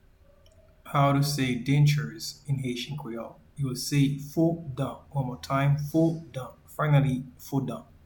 Pronunciation and Transcript:
Dentures-in-Haitian-Creole-Fo-dan.mp3